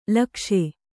♪ lakṣe